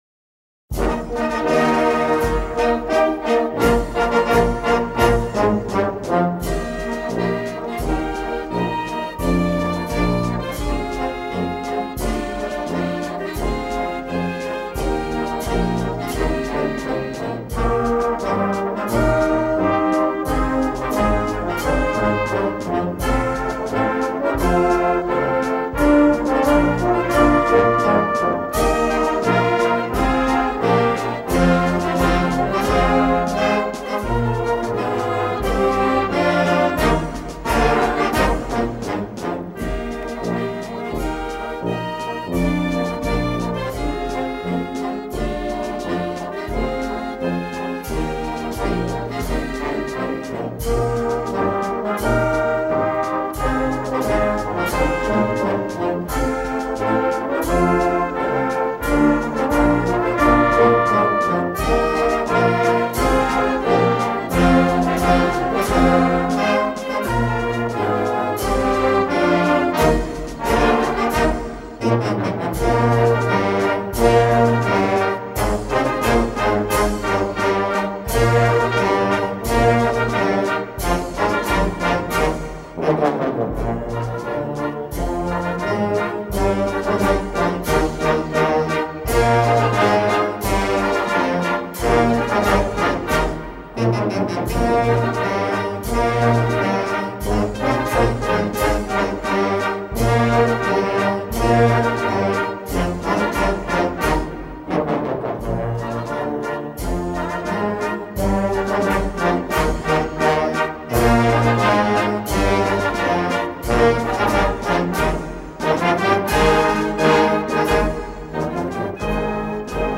Narrenmarsch